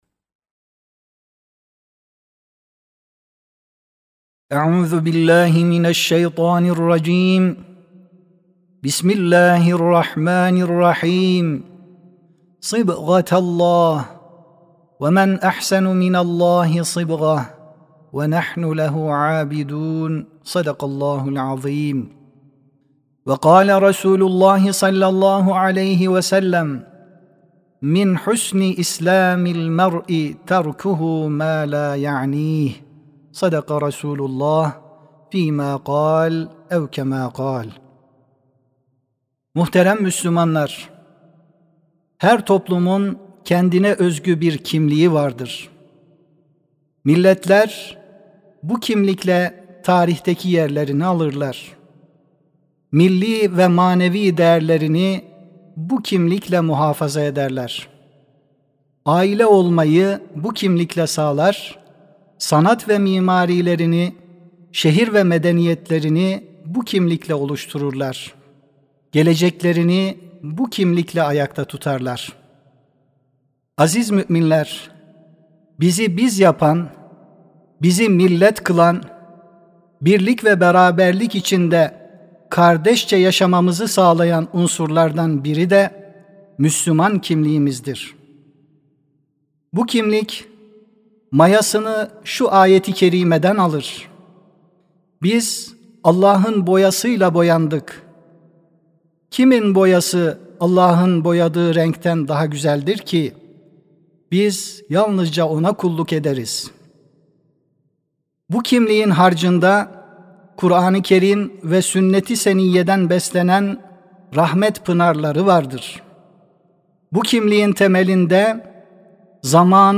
Sesli Hutbe (Kimliğimiz Geleceğimizdir ).mp3